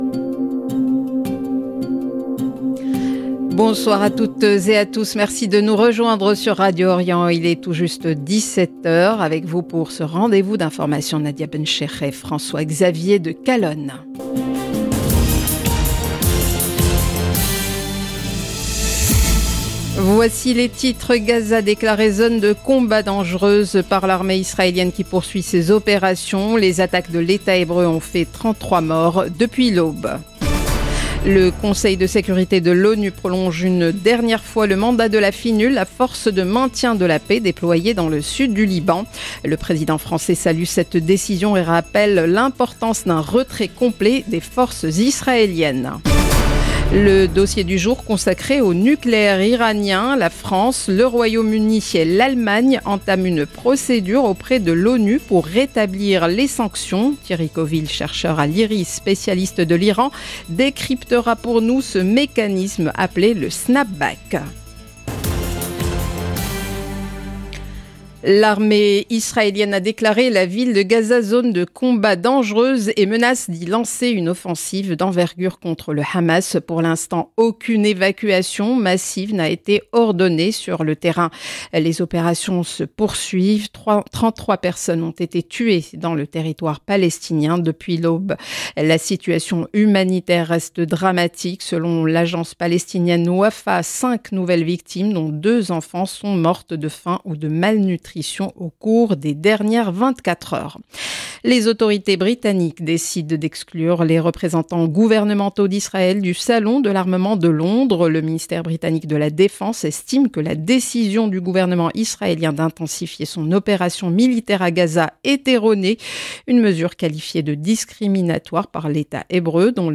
Journal d'information de 17H du 29 août 2025